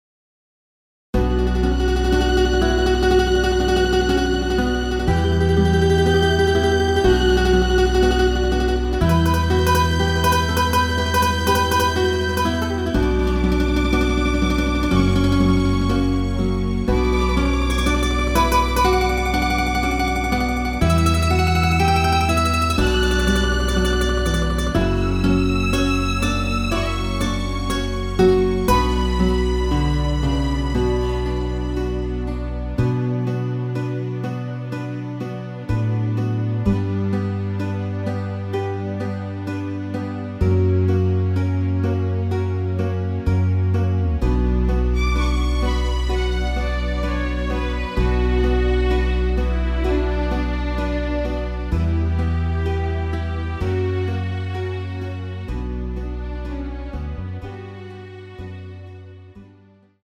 원키에서(+1)올린 MR입니다.
Bm
앞부분30초, 뒷부분30초씩 편집해서 올려 드리고 있습니다.
중간에 음이 끈어지고 다시 나오는 이유는